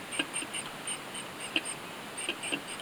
雌が巣内に入ったり巣材を運んだりする営巣の前の数日間、オスが巣箱に入り数秒間中を見回したりつついたりしている時に鳴き続ける。